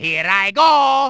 One of Wario's voice clips in Mario Kart 64